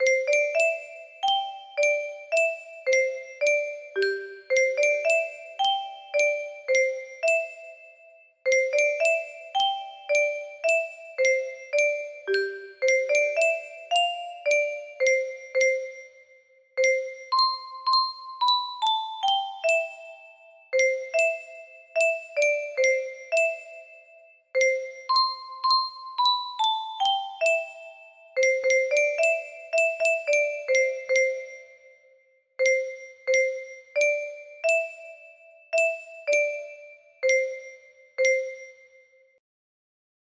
So-La-so lullaby music box melody